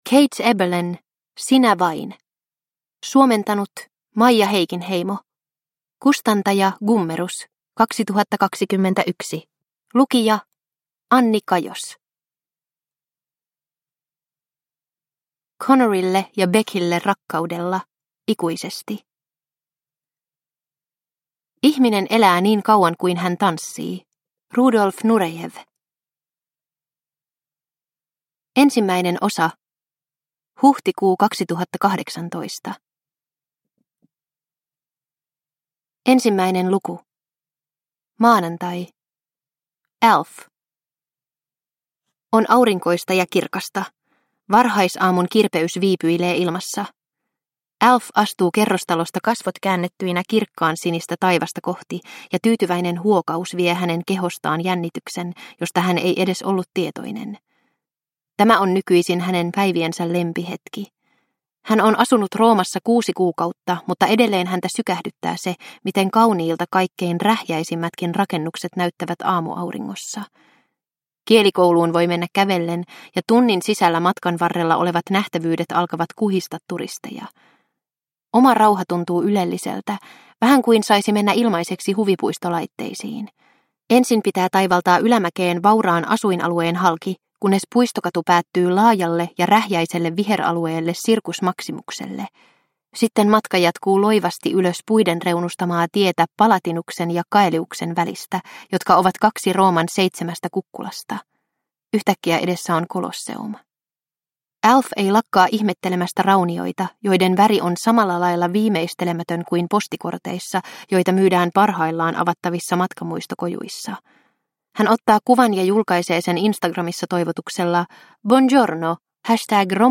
Sinä vain – Ljudbok – Laddas ner